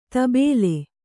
♪ tabēle